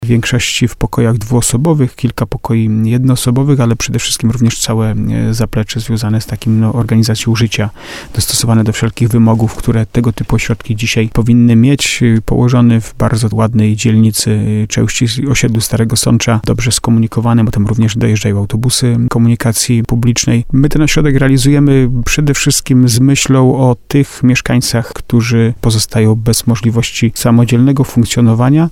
Budujemy ten ośrodek z myślą o mieszkańcach gminy Stary Sącz, którzy na danym etapie swojego życia nie mogą samodzielnie funkcjonować – mówi Jacek Lelek, burmistrz Starego Sącza.